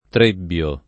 tr%bbLo] s. m.; pl. ‑bi — ant. allòtropo pop. di trivio, con uguale sign. proprio («incrocio di strade») e con differente sign. fig. («luogo di ritrovo; convegno di persone»): uno trebbio di via, per lo quale si poteva andare a Siena, a Firenze e ad Arezzo [